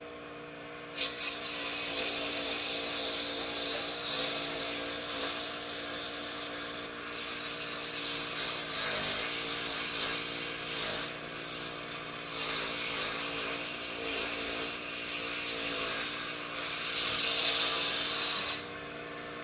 房屋 " 割草机
Tag: 切割机 草坪 割草机 刀片 剪草机 花园 草坪